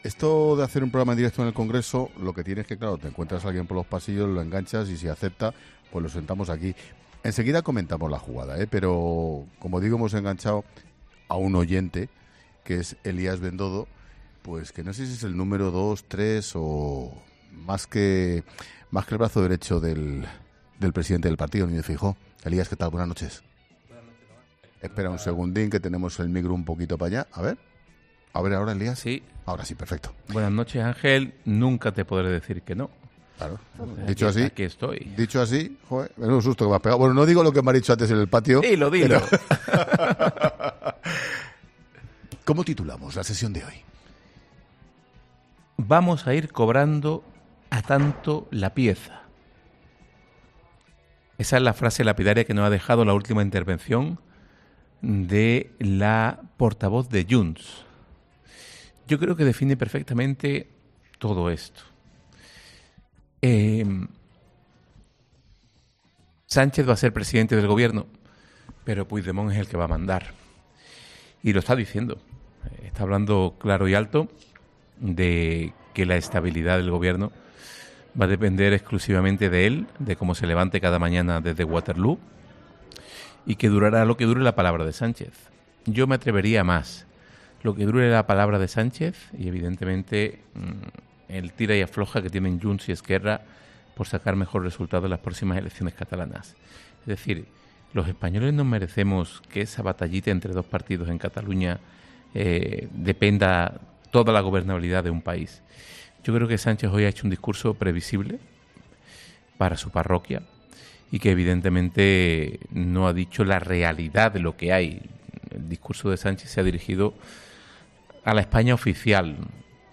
El comunicador de COPE entrevistaba al político malagueño en el mismo Congreso de los Diputados, desde donde ha emitido el programa este miércoles con motivo del inicio del proceso de investidura a Sánchez: “Está hablando claro y alto de que la estabilidad del Gobierno va a depender exclusivamente de Puigdemont y cómo se levante cada mañana, y durará lo que dure la palabra de Sánchez y el tira y alfoja de Junts y ERC por los resultados de las elecciones catalanas”, añadía Bendodo.